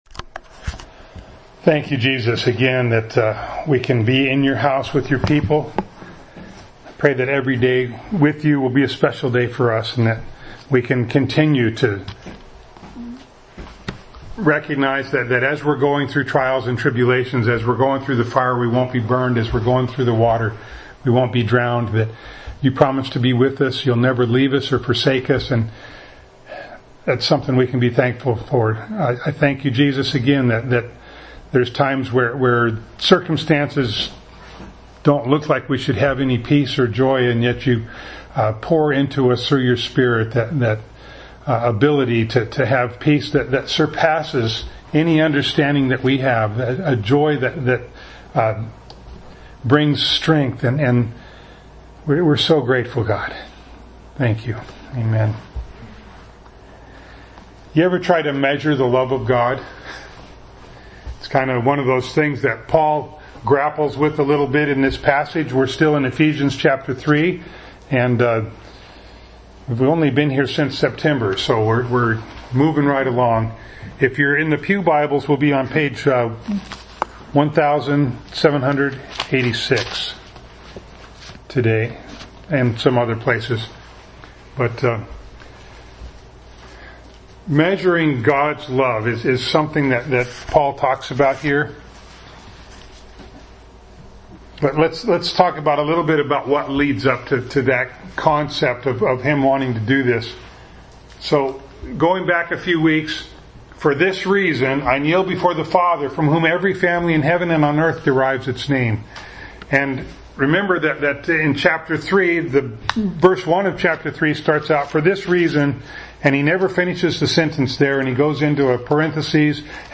Ephesians 3:17b-18 Service Type: Sunday Morning Bible Text